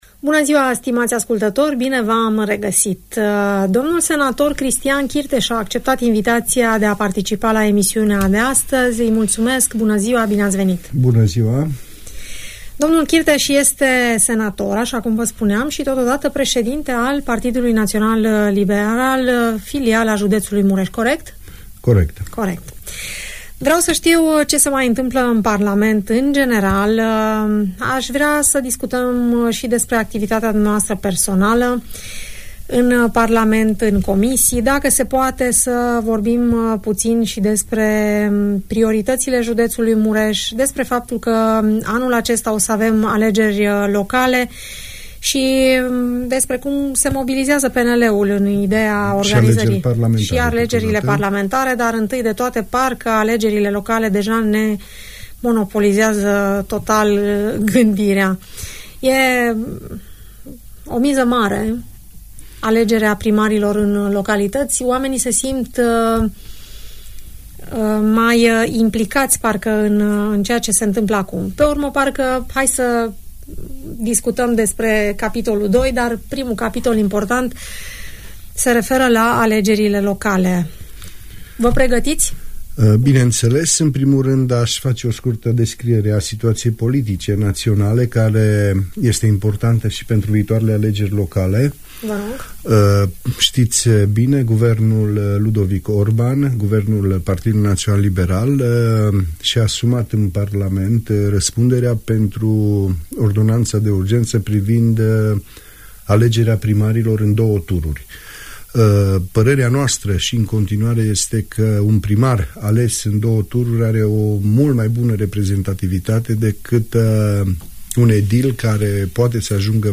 Senatorul Partidului Național Liberal, Cristian Chirteș, vorbește despre activitatea parlamentară și guvernamentală în general și despre proiectele personale în interesul cetățenilor județului Mureș.